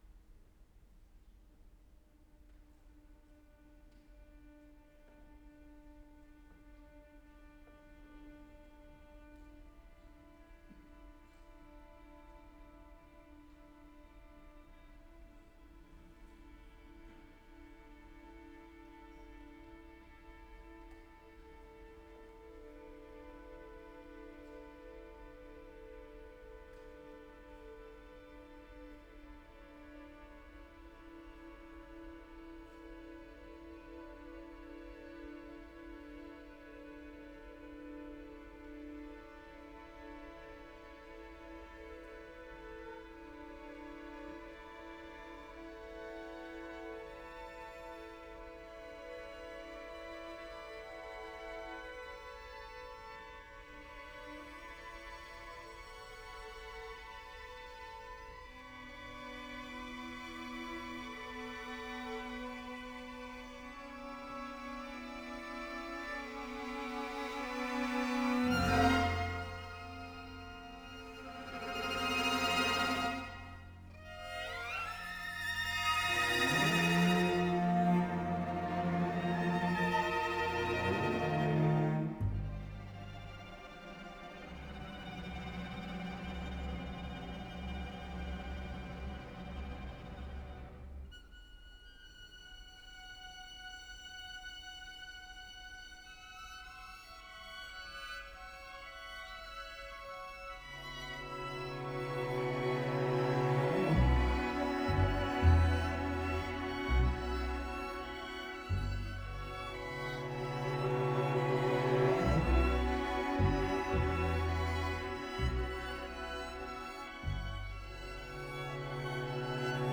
versão para 8 cellos